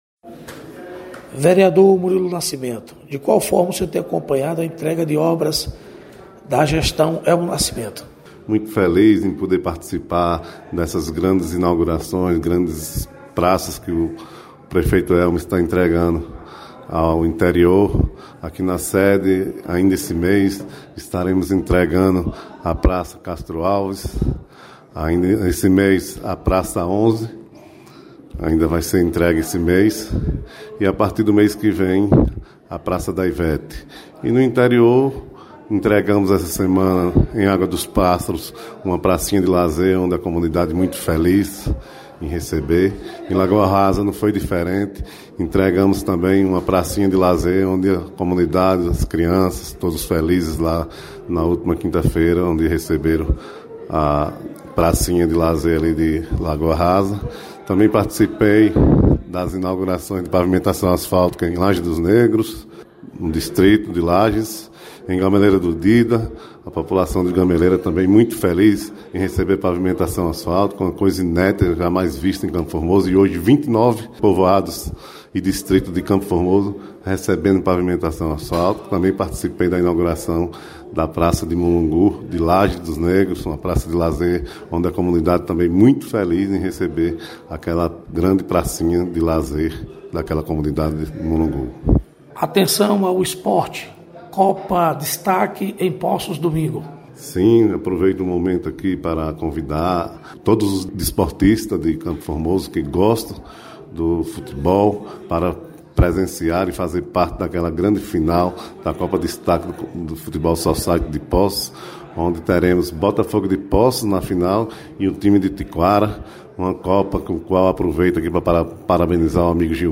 Reportagem com os Vereadores de Campo Formoso